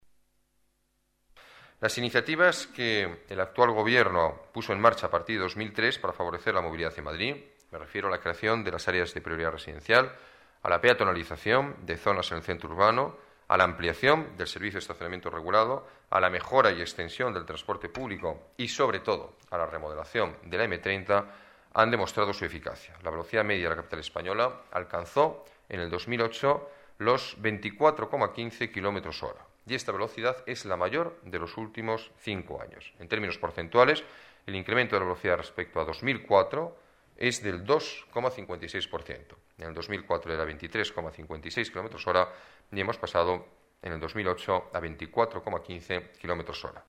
Nueva ventana:Declaraciones alcalde, Alberto Ruiz-Gallardón: aumento velocidades tráfico